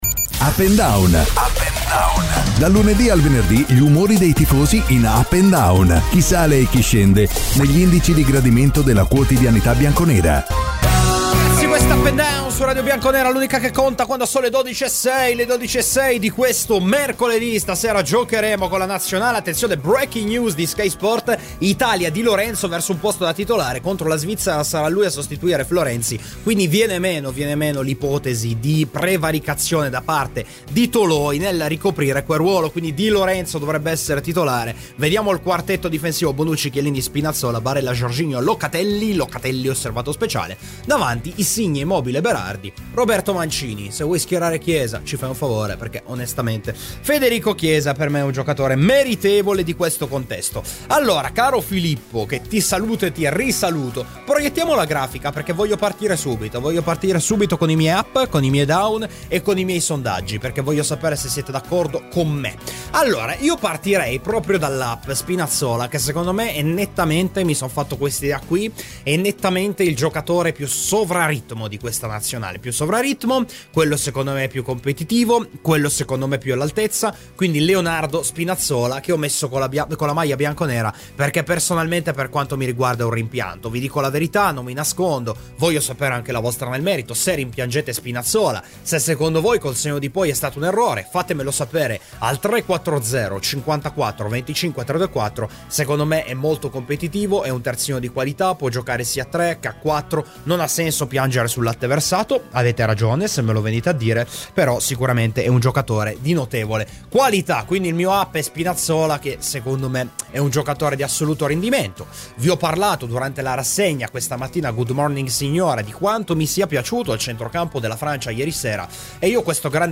Ospite : Giancarlo Abete (ex presidente FIGC) © registrazione di Radio Bianconera Facebook twitter Altre notizie